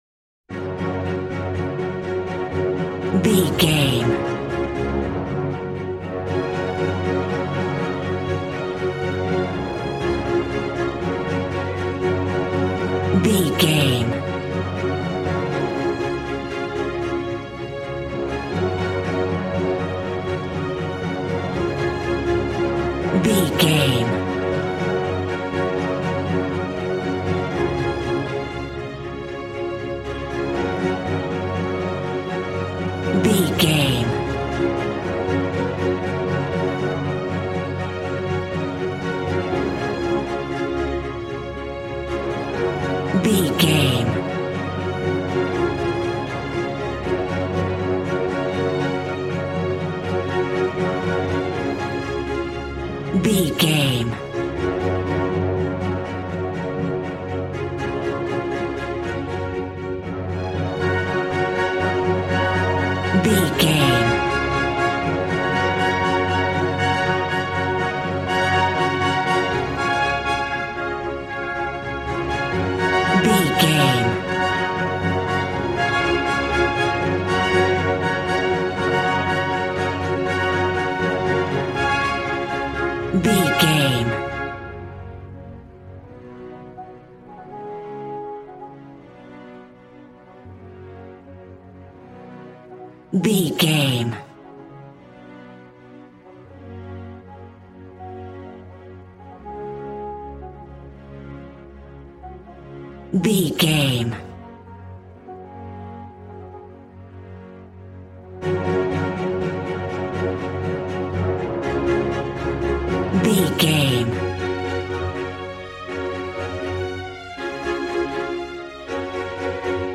Ionian/Major
G♭
positive
cheerful/happy
joyful
drums
acoustic guitar